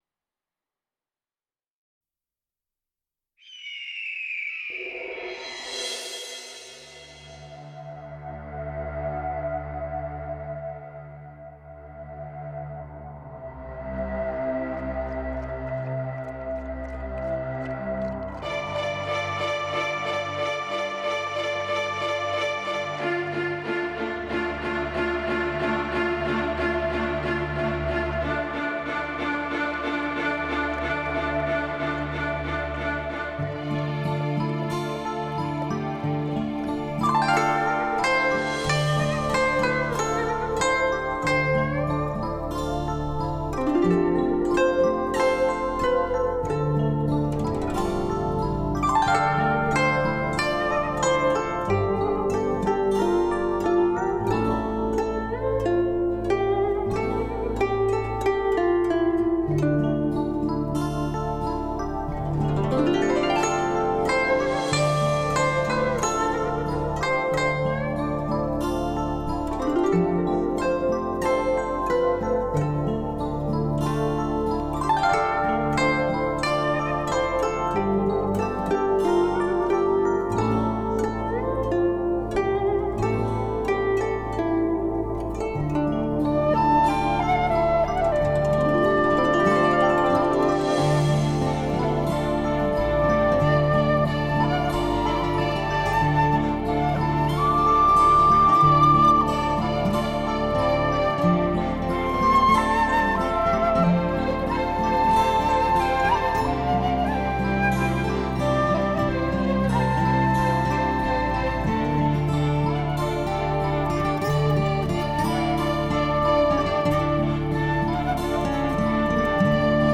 心底洋溢起温暖而美妙的音符，情触弦动时，轻盈如云，婉约如诗……